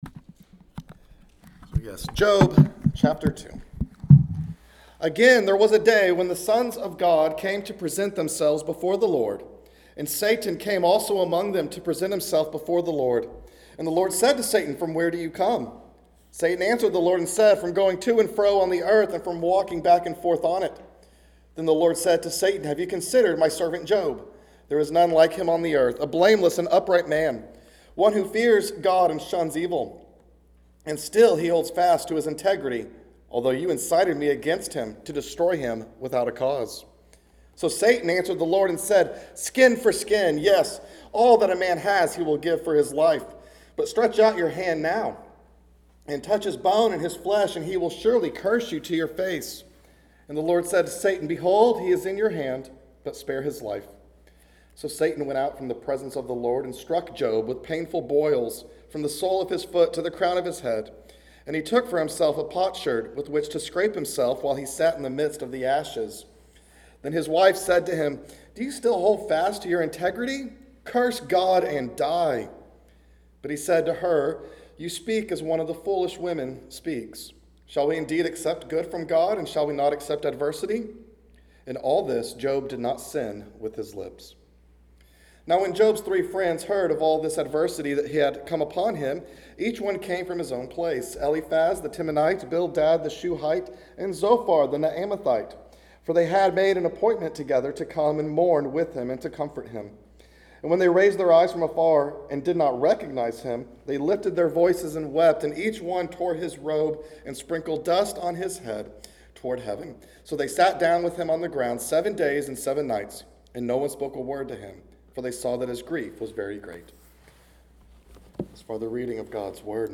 A message from the series "Job."